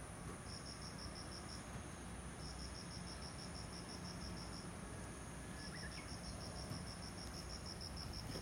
もう一つの悩みは、近所にいる虫の鳴き声が煩いことだ。
声の主は分からないが、「リリリリ・・・（一拍置いて）リリリリ・・・」という音を発し続けている。タイマーが永遠に鳴っているような錯覚に陥るため、どうしても気になってしまう。
（添付の音声が虫の鳴き声）。